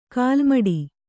♪ kālmaḍi